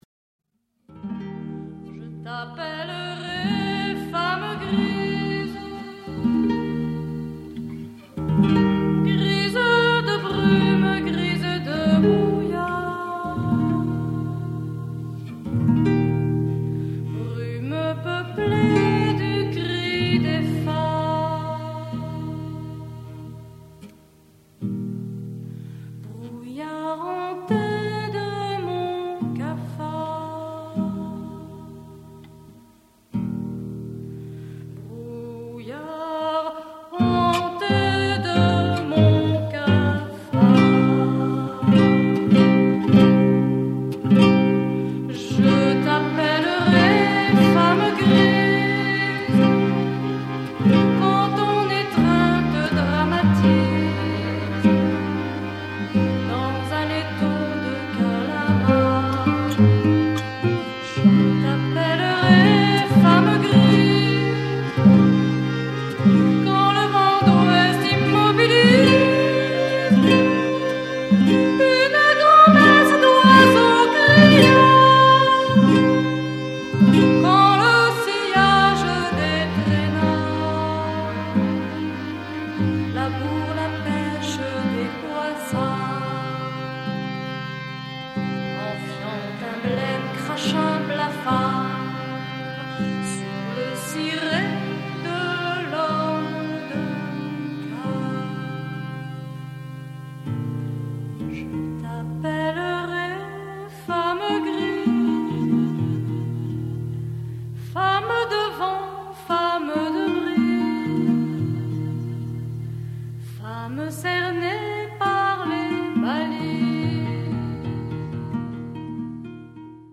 Chanson française
guitares
flûte traversière